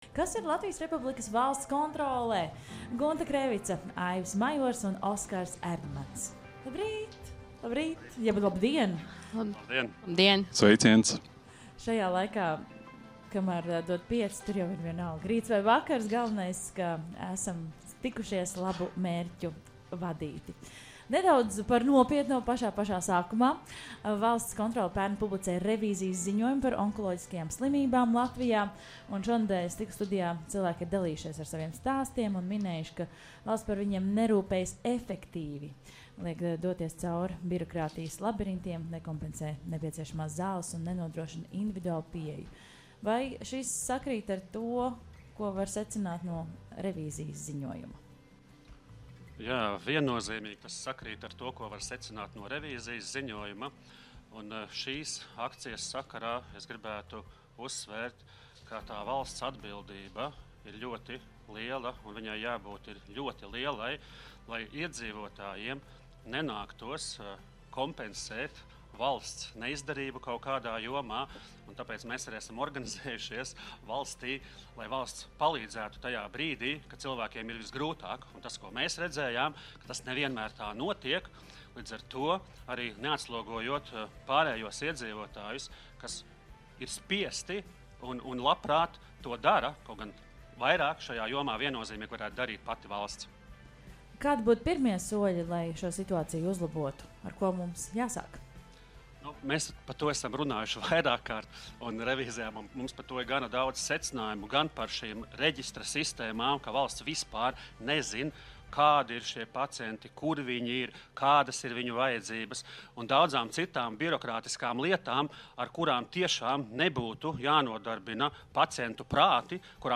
Labdarības maratona "Dod pieci" stikla studijā viesojas Valsts kontroles pārstāvji